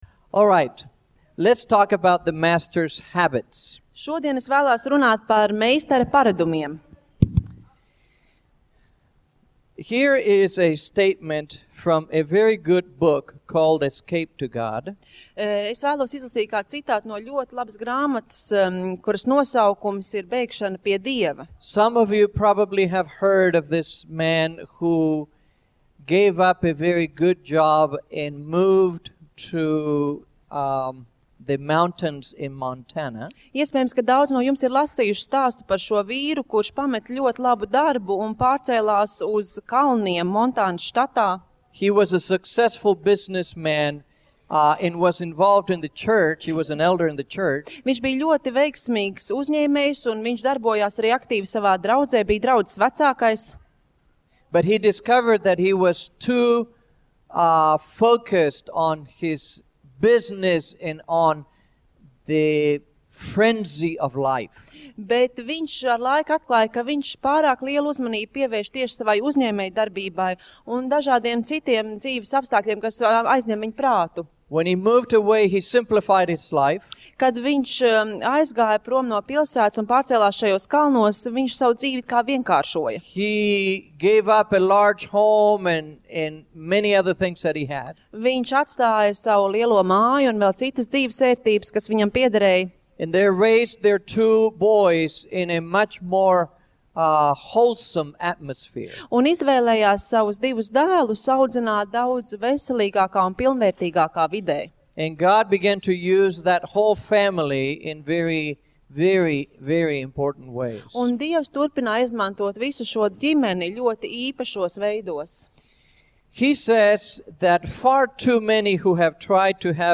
Seminārs - Neatklātais stāsts par nepabeigto darbu